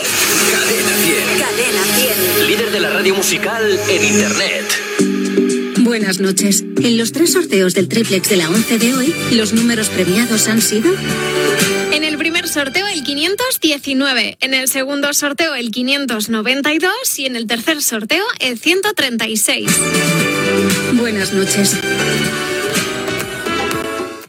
Indicatiu de la ràdio, resultat del sorteig de l'ONCE.